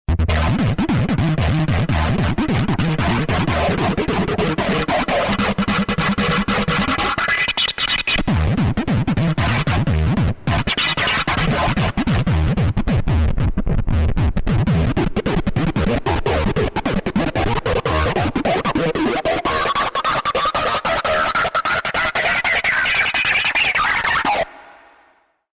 Budget rompler synthesizer dance/techno oriented with some real-time options.
distortion *